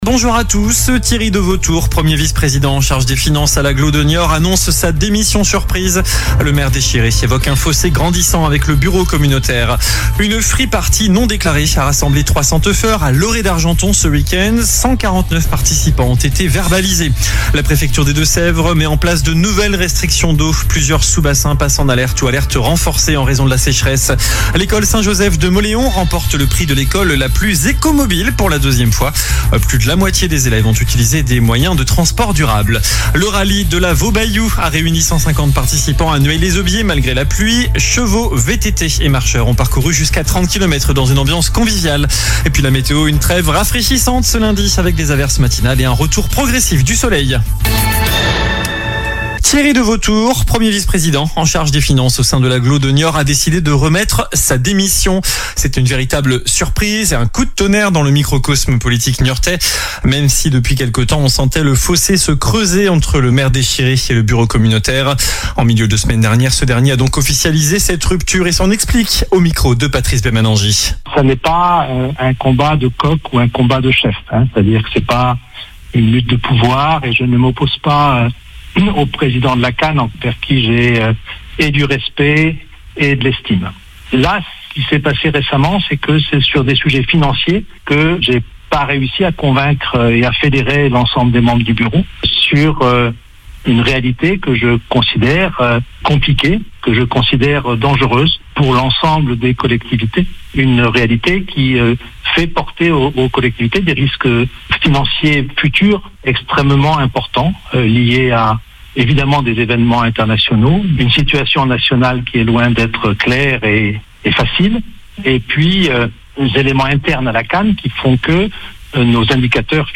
Journal du lundi 7 juillet